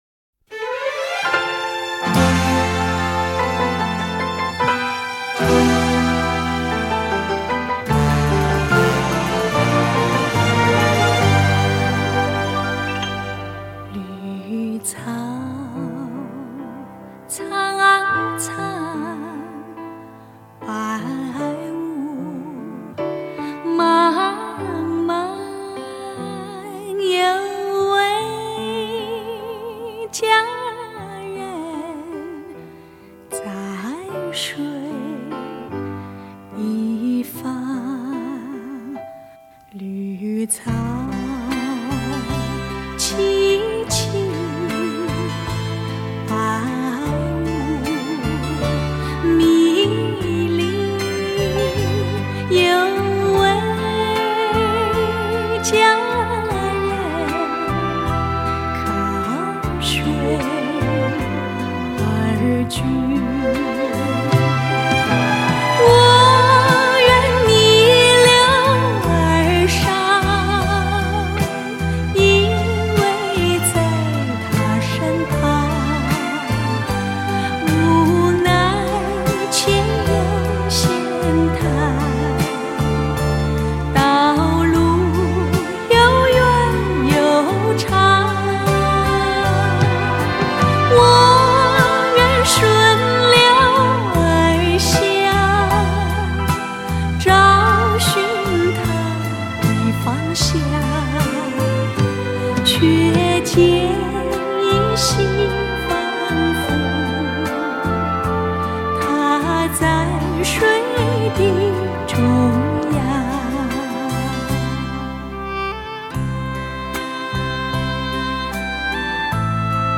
DXD重新编制